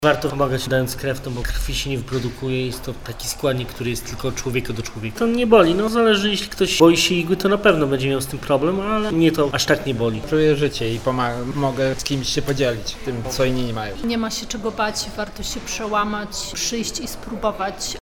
Z krwiodawcami rozmawiał